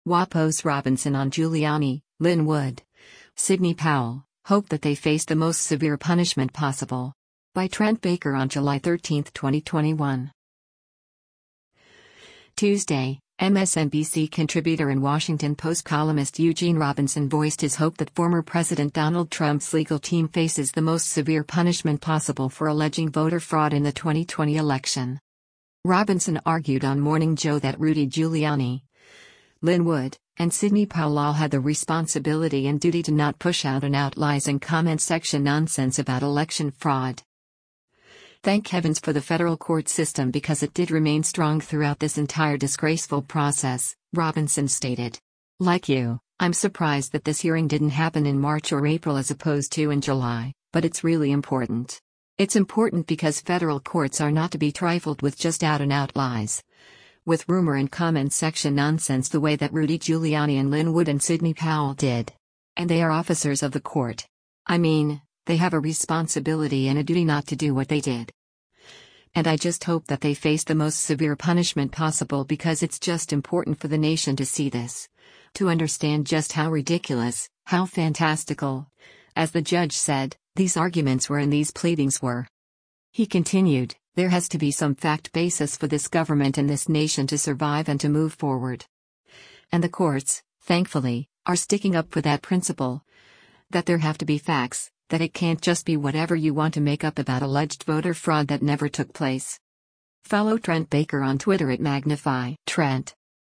Robinson argued on “Morning Joe” that Rudy Giuliani, Lin Wood, and Sidney Powell all had the “responsibility” and “duty” to not push “out-and-out lies” and “comment section nonsense” about election fraud.